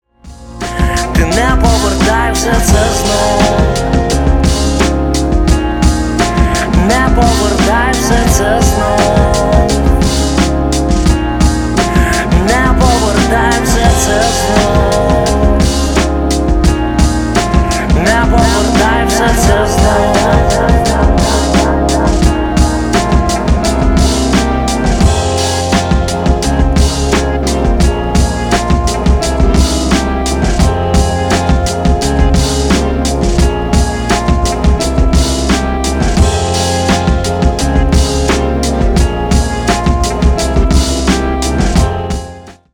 душевные
Alternative Rock
украинский рок
indie rock